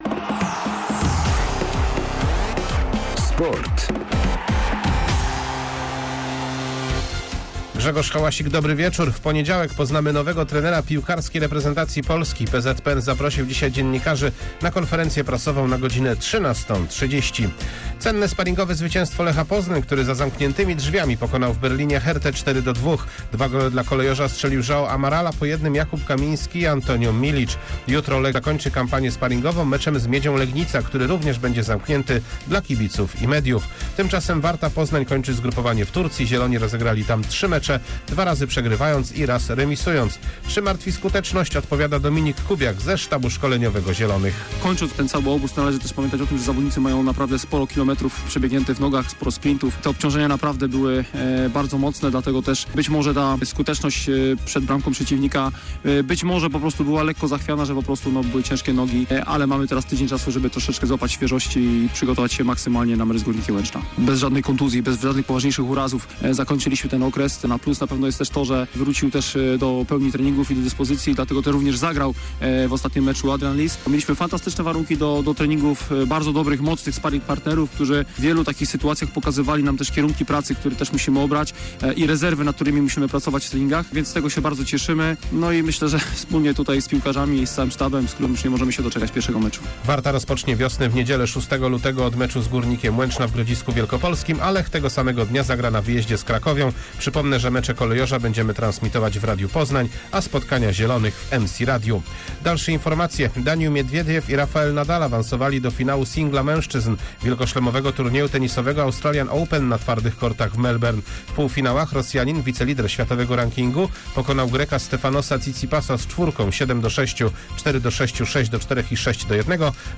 28.01.2022 SERWIS SPORTOWY GODZ. 19:05